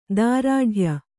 ♪ dārāḍhya